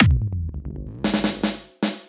115 BPM Beat Loops Download